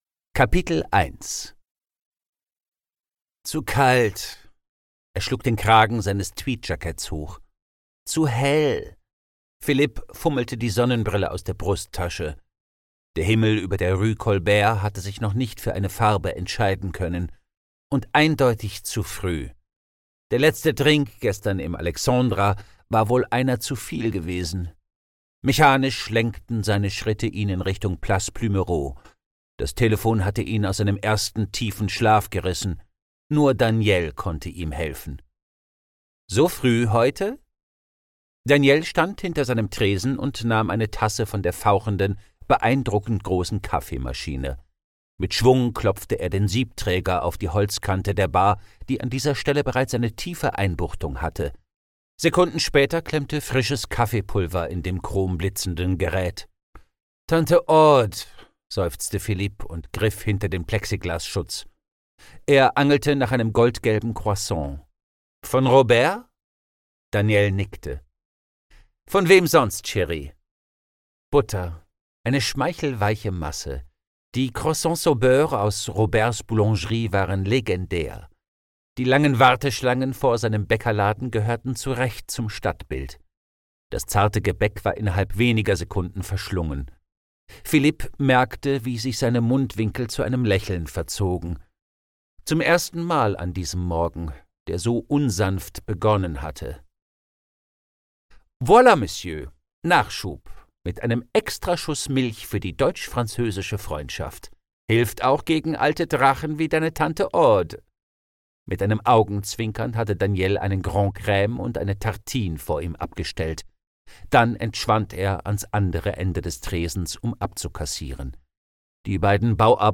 Themenwelt Literatur Krimi / Thriller / Horror Historische Kriminalromane